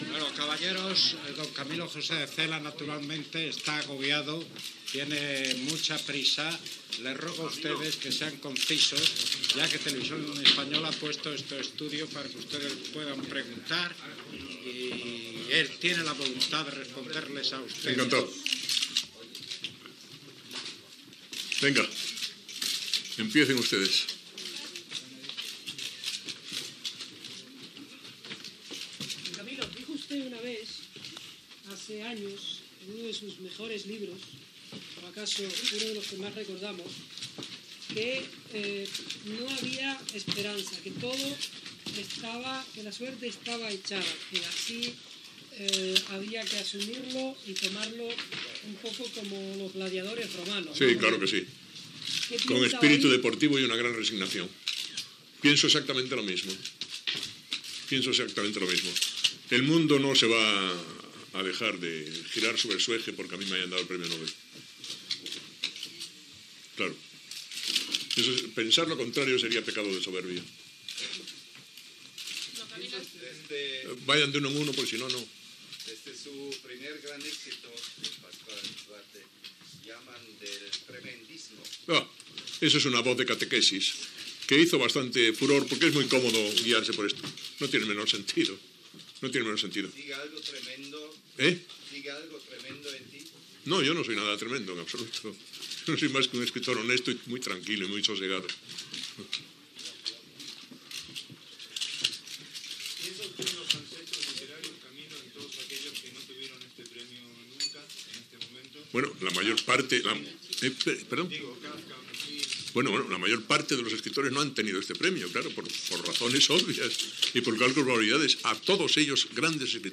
Roda de premsa de Camilo José Cela en ser proclamat Premi Nobel de Literatura. Feta a la seu de TVE de Torrespaña de Madrid (abans de participar al Telediario)
Informatiu